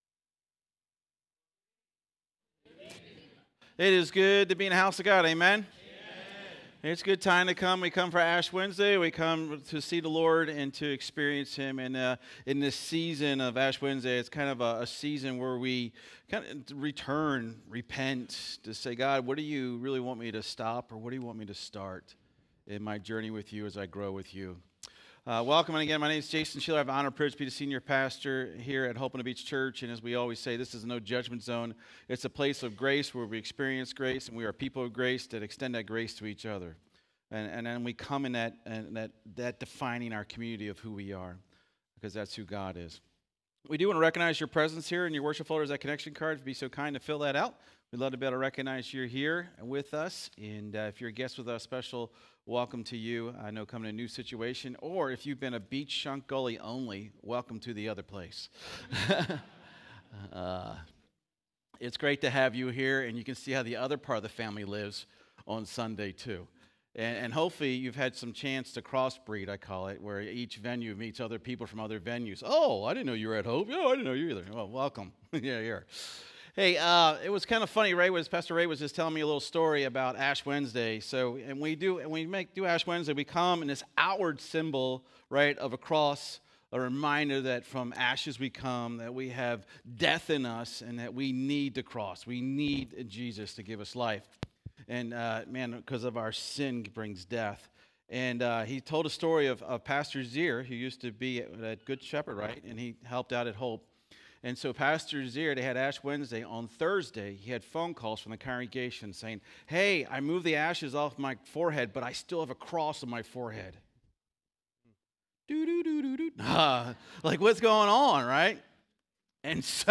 SERMON DESCRIPTION Ash Wednesday is a time for renewal as we turn our hearts to Jesus and rededicate our lives to the Gospel that has saved us.